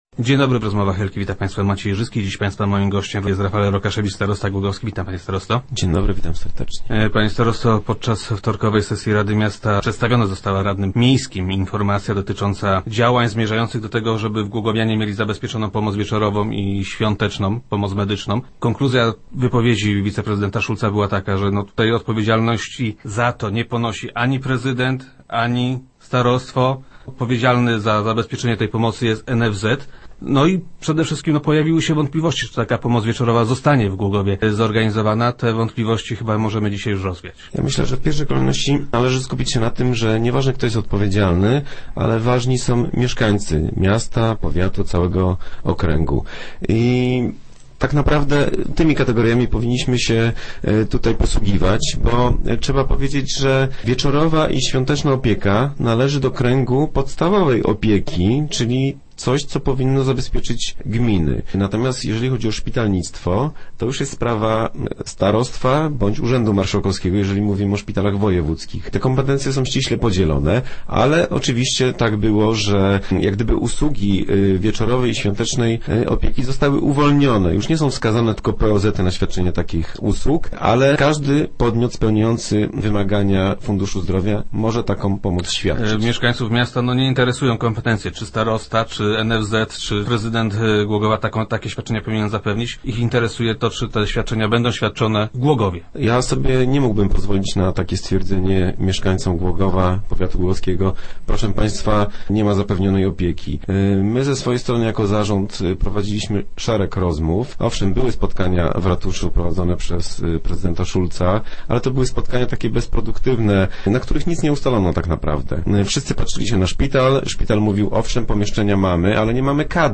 - Zgłosiło się do niego konsorcjum lekarzy z głogowskiego ZOZ-u - informuje Rafael Rokaszewicz, starosta głogowski, który był gościem Rozmów